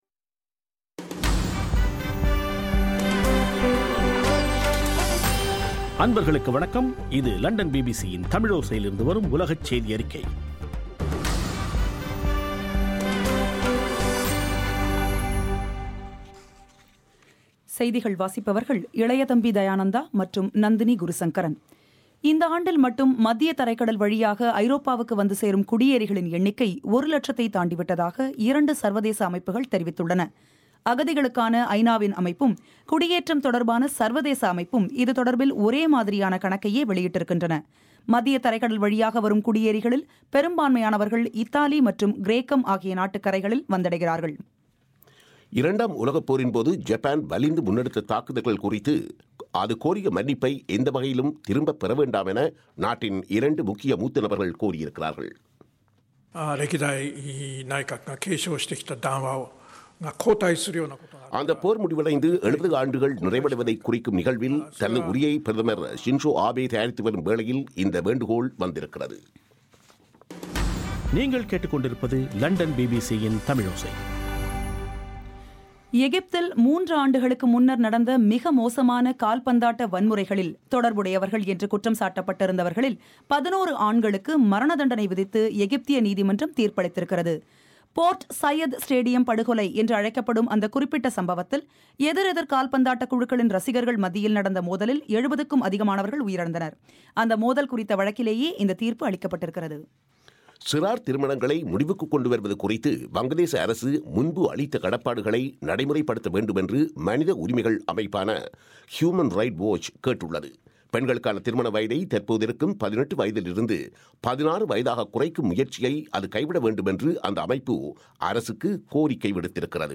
ஜூன் 9 பிபிசியின் உலகச் செய்திகள்